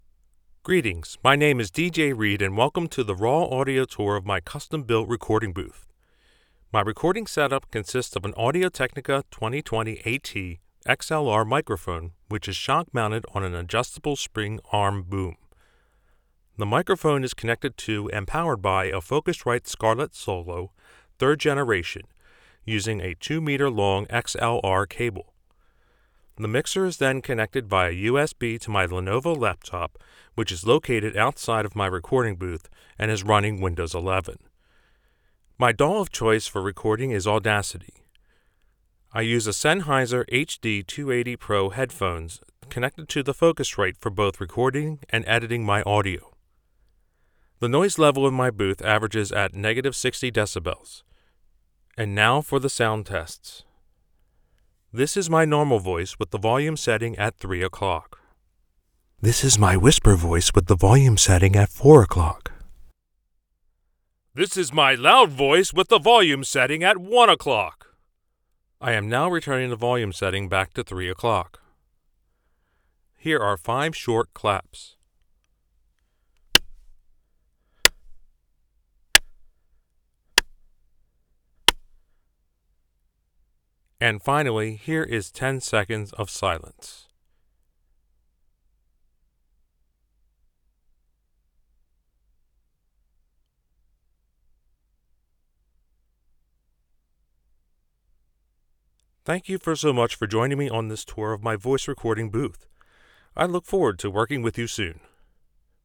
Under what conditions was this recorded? • AudioTechnica AT2020 XLR Microphone • Custom Sound Recording Booth